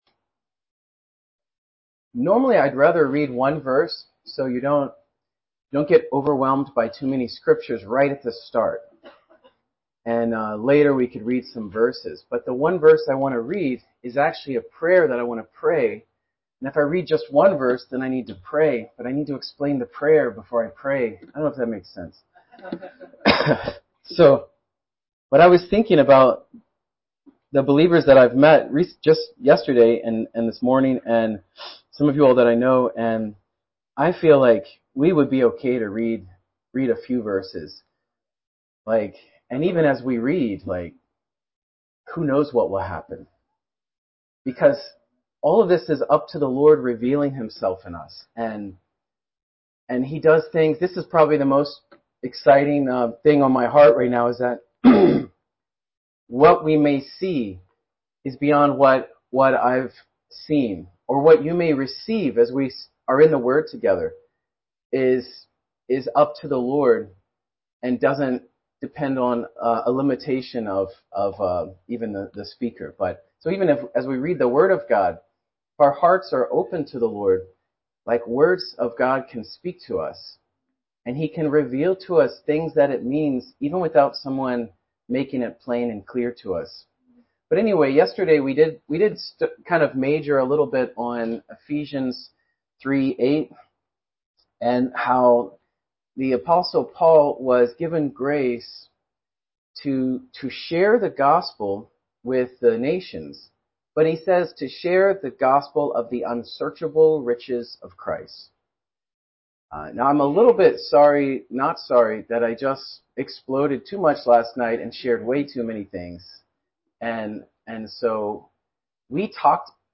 Winter Youth Conference